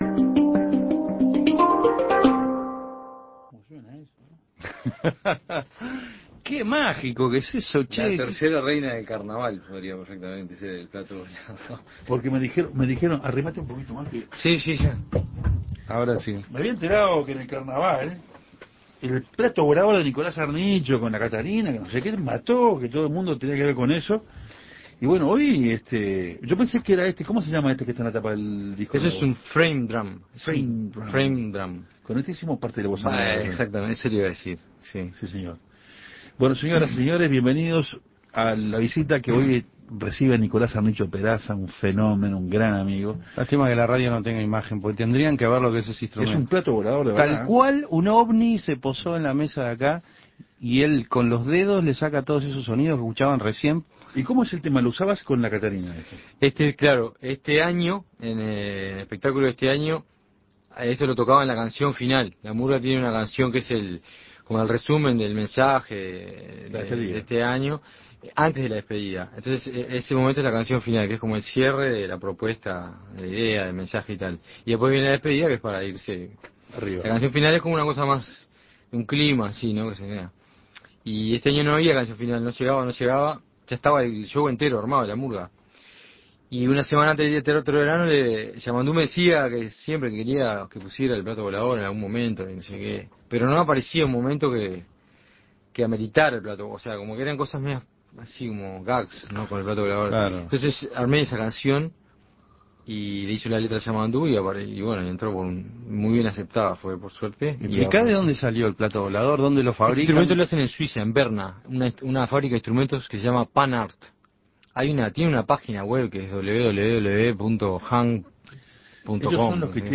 El Hang Drum, peculiar instrumento que aprendió a tocar en uno de sus viajes por el mundo, es uno de los protagonistas en esta entrevista que los periodistas de Otra Historia le hicieron al destacado músico.